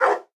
sounds / monsters / dog
bdog_idle_4.ogg